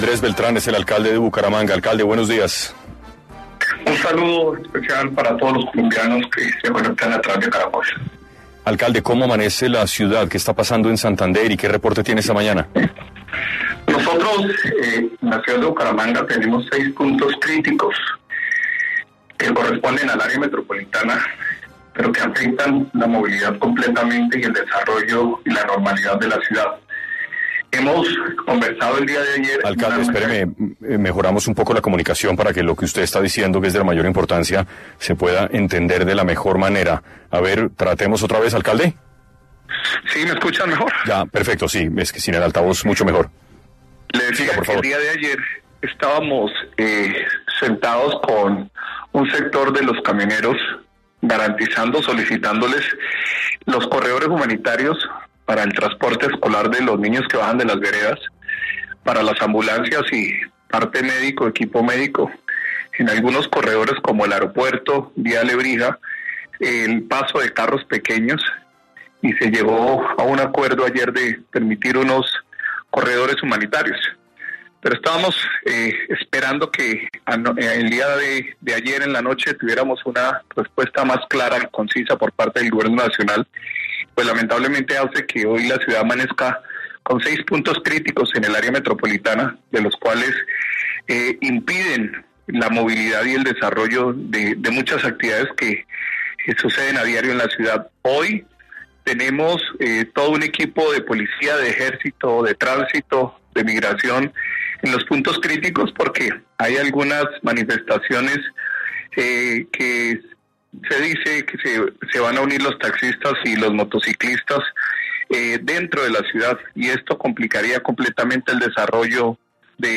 En diálogo con 6AM Hoy por Hoy, Jaime Andrés Beltrán, alcalde de Bucaramanga presentó un balance general de la situación en esta región donde se reporta el cierre vial en la vía entre Girón y Lebrija, además de afectaciones en seis puntos del área metropolitana: